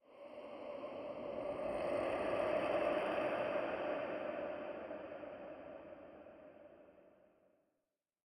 Minecraft Version Minecraft Version latest Latest Release | Latest Snapshot latest / assets / minecraft / sounds / ambient / nether / soulsand_valley / voices2.ogg Compare With Compare With Latest Release | Latest Snapshot
voices2.ogg